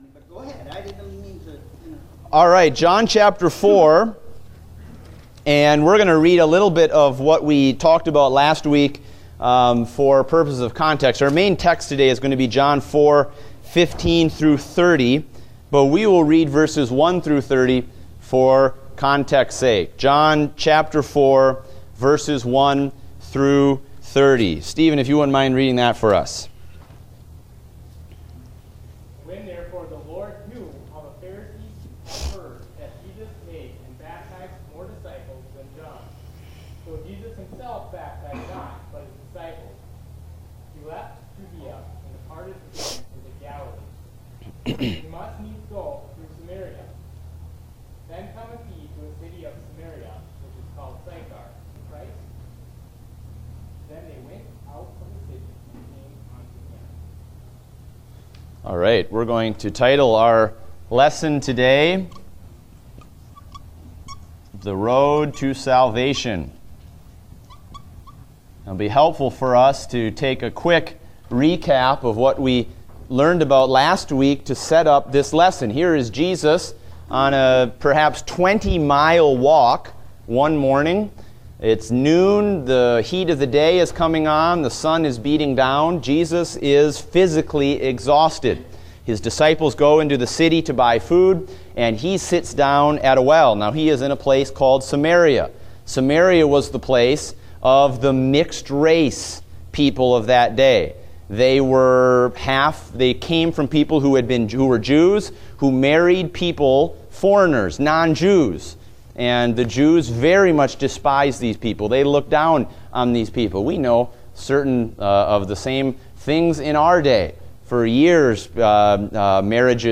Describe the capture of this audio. Date: June 19, 2016 (Adult Sunday School)